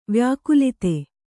♪ vyākulite